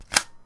shotgun_put_ammo-4.wav